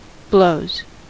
Ääntäminen
Ääntäminen US Tuntematon aksentti: IPA : /bləʊz/ Haettu sana löytyi näillä lähdekielillä: englanti Blows on sanan blow monikko.